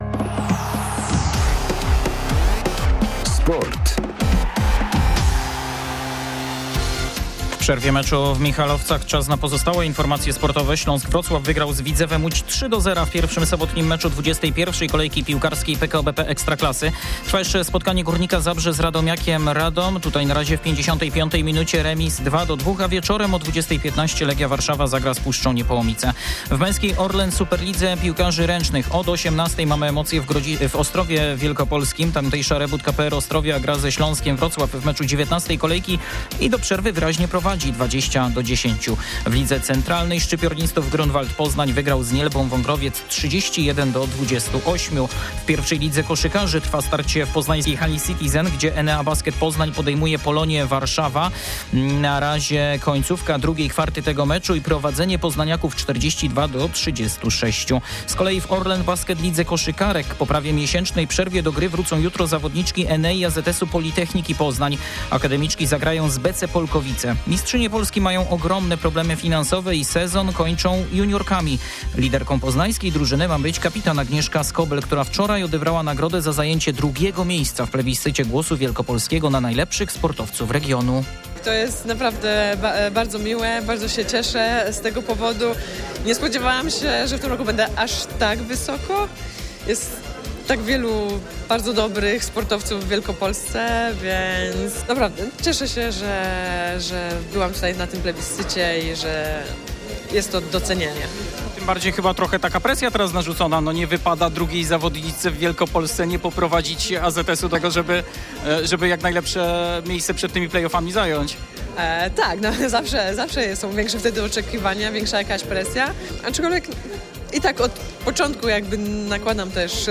15.02.2025 SERWIS SPORTOWY GODZ. 18:45
Serwis sportowy w przerwie meczu Iuventa Michalovce - MKS Urbis Gniezno.